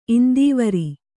♪ indīvari